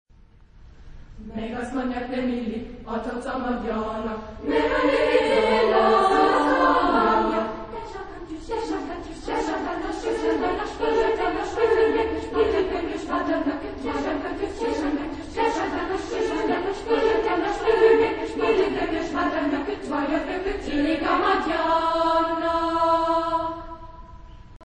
Genre-Style-Form: Choral song ; ballet ; Canon ; Secular
Type of Choir: SSSAA  (5 women voices )
Tonality: A minor
Discographic ref. : 7. Deutscher Chorwettbewerb 2006 Kiel